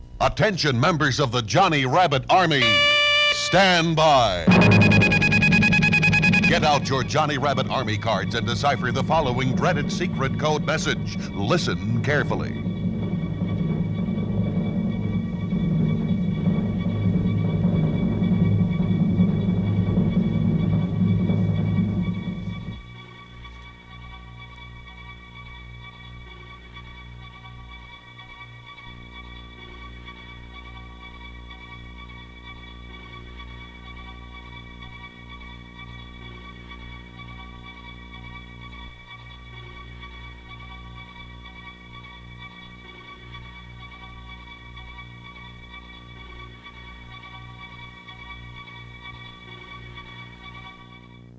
radio promo